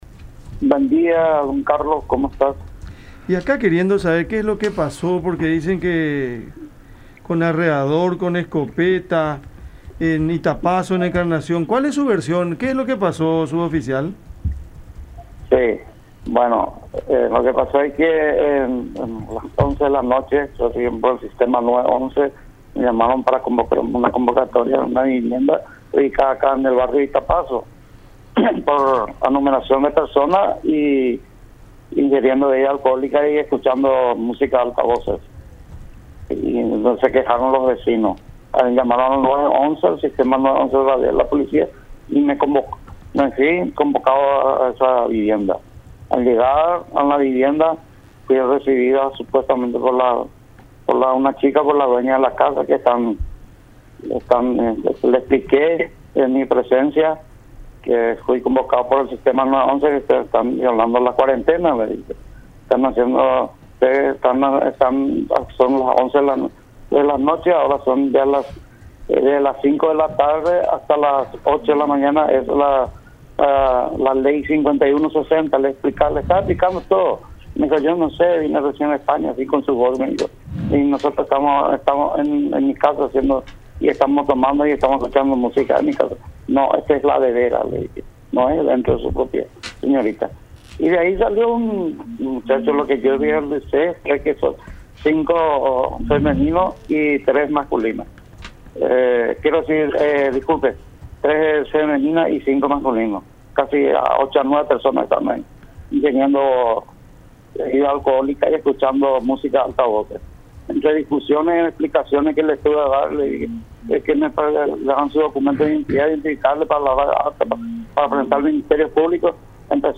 en conversación con el programa Cada Mañana por La Unión